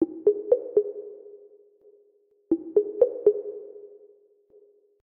dialing.ogg